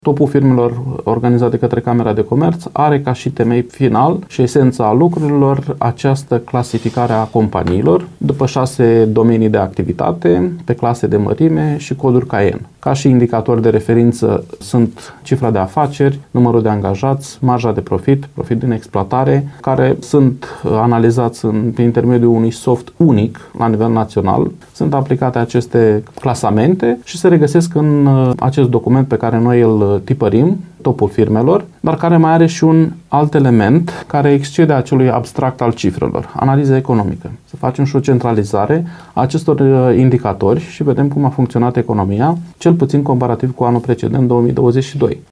a declarat, la Radio VIVA FM, că evenimentul va aduce în prim-plan antreprenori care, prin perseverență și viziune, au transformat provocările în oportunități, demonstrând că afacerile locale pot concura cu succes atât la nivel național, cât și internațional.